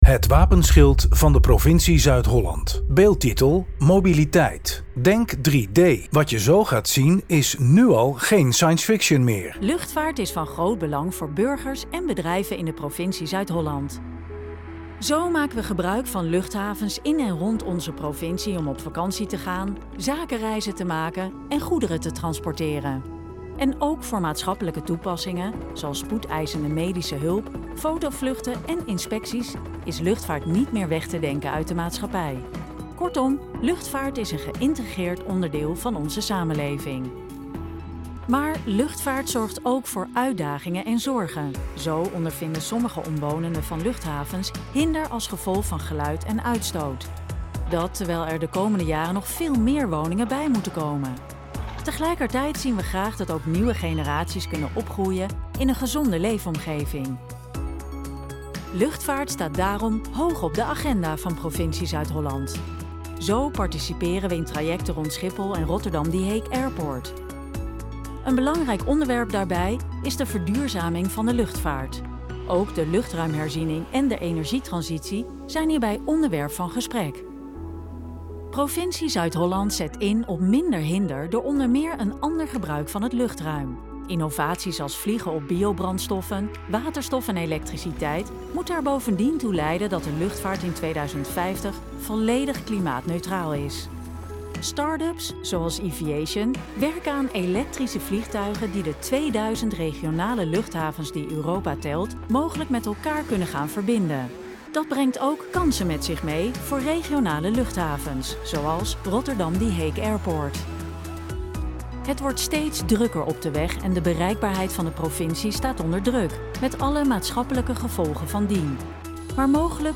audiodescriptie_mp3.mp3